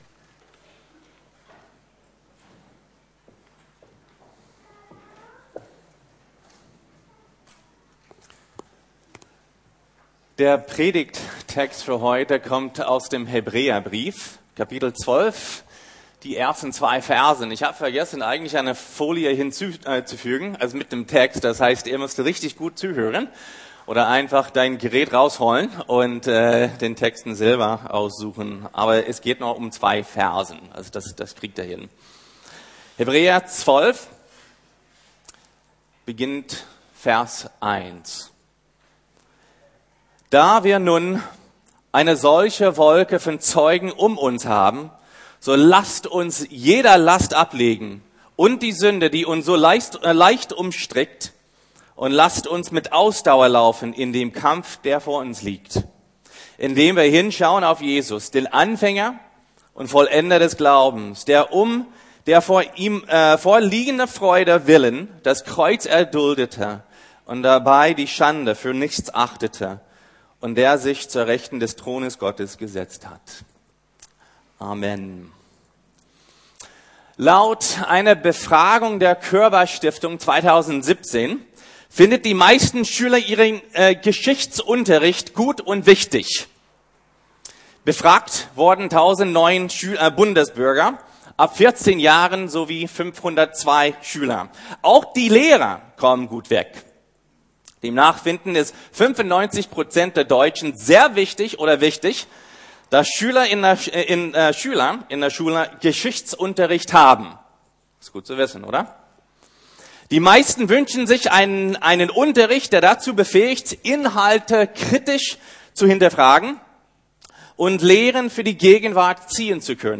Die Weltgeschichte in wenigen Minuten ~ Predigten der LUKAS GEMEINDE Podcast